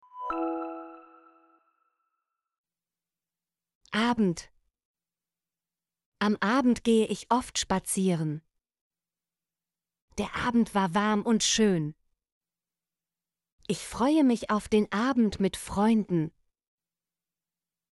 abend - Example Sentences & Pronunciation, German Frequency List